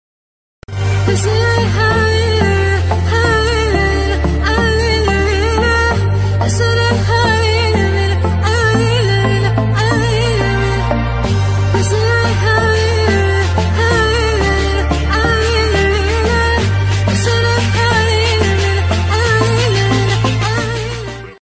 Arabic Ringtones